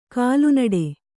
♪ kālunaḍe